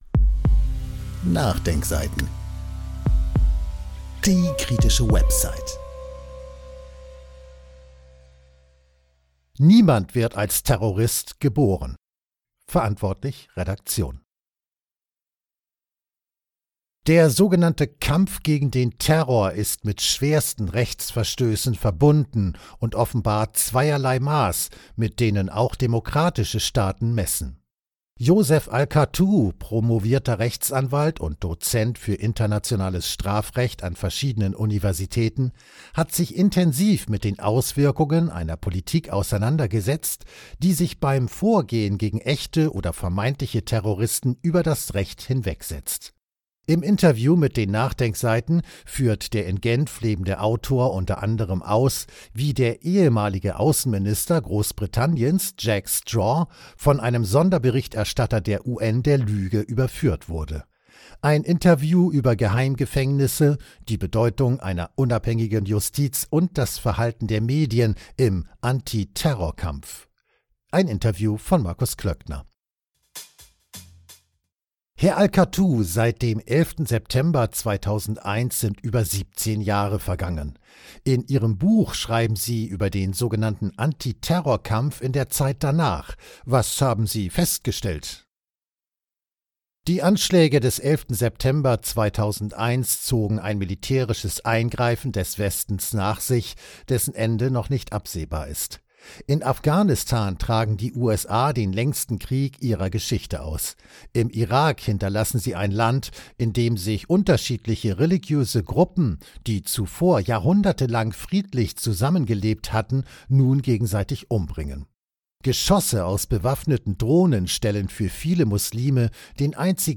Ein Interview über Geheimgefängnisse, die Bedeutung einer unabhängigen Justiz und das Verhalten der Medien im „Anti-Terror-Kampf.“